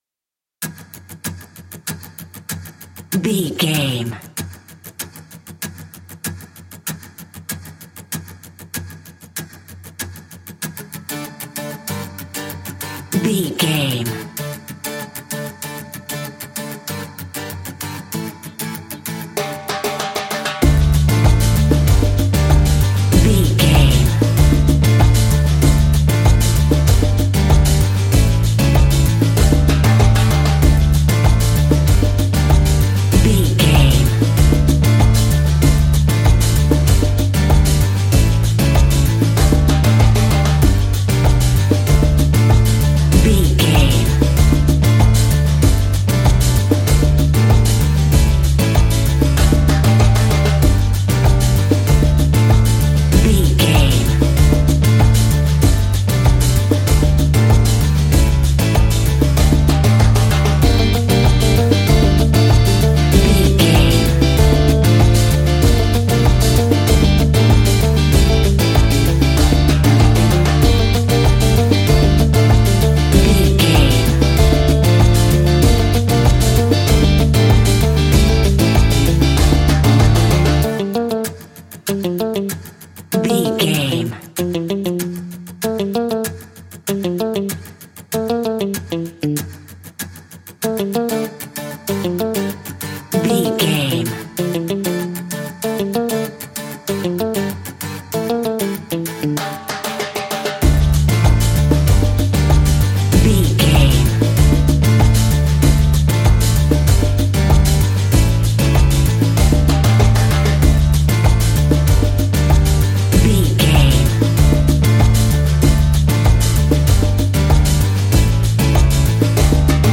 Aeolian/Minor
steelpan
worldbeat
drums
percussion
bass
brass
guitar